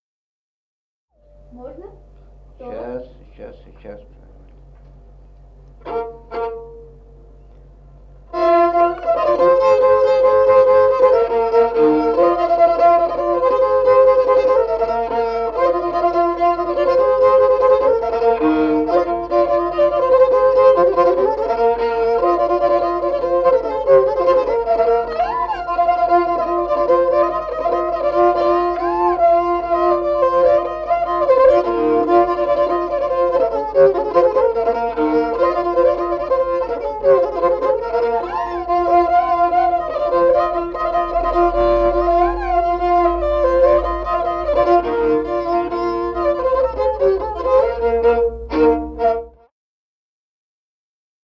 Музыкальный фольклор села Мишковка «Журавель», партия 2-й скрипки.